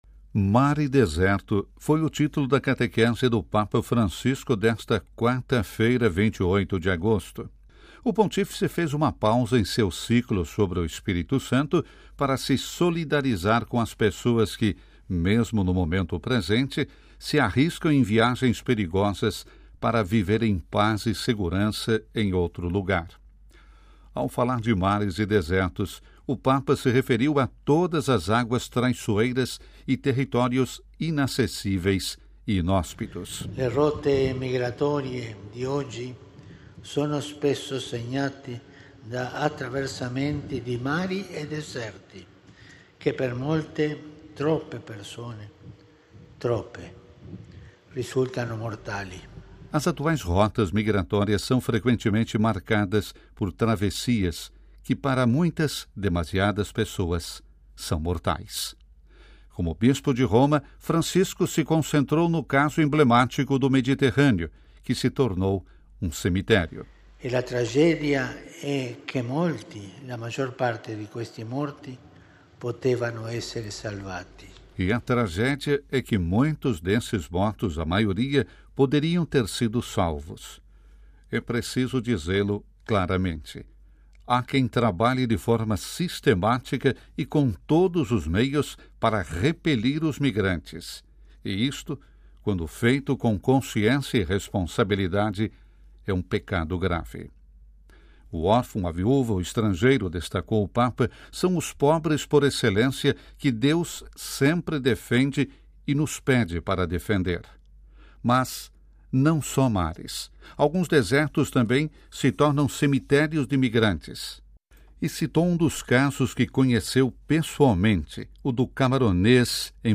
A Audiência Geral voltou à Praça São Pedro esta quarta-feira, 28 de agosto. O Pontífice dedicou sua catequese ao drama dos migrantes: que mares e desertos não se tornem cemitérios.